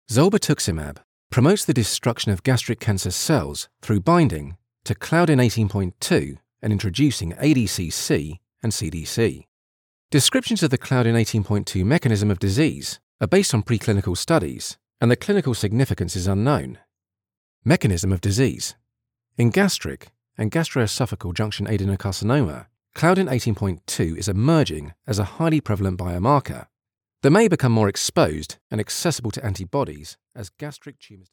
Medizinische Erzählung
Warm, voll und ohne regionalen Akzent. Er arbeitet von seinem Heimstudio aus und verwendet nur die beste Ausrüstung.
Ich verwende ein Sennheiser MKH-416-Mikrofon, Audacity, Focusrite Scarlett Solo und habe ein komplett ausgestattetes Aufnahmestudio.